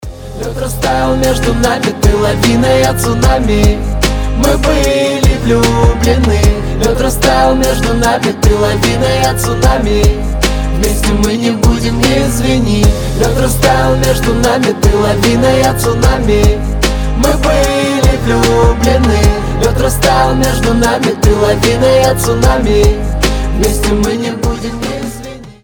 • Качество: 320, Stereo
красивые
лирика
дуэт